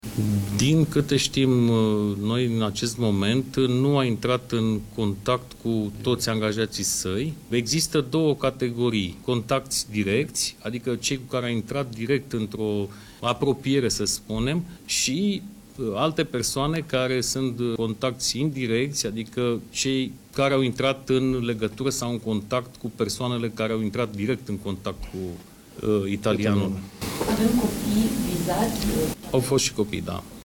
Reprezentantul Guvernului spune că autorităţile locale încearcă acum să-i găsească pe toţi cei care au intrat în contact cu italianul care a stat peste patru zile în România şi care a fost depistat în Italia cu noul coronavirus: